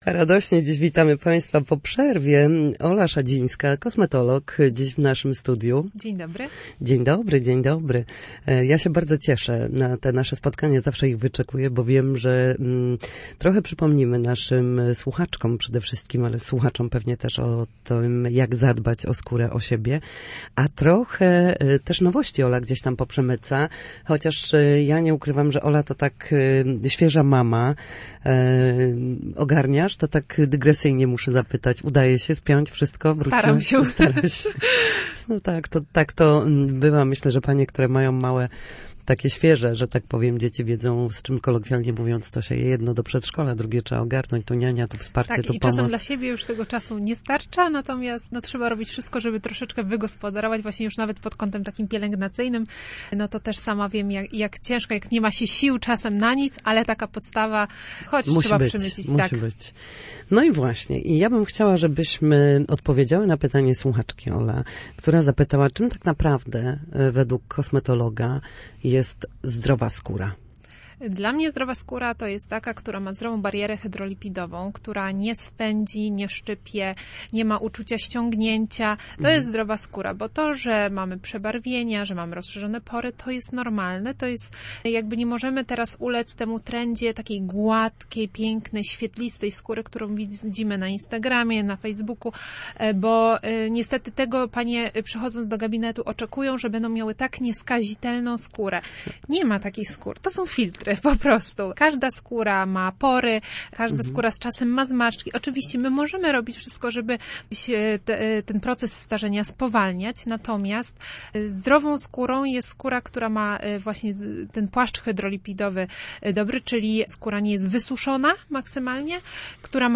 W każdą środę, w popołudniowym Studiu Słupsk Radia Gdańsk dyskutujemy o tym, jak wrócić do formy po chorobach i urazach.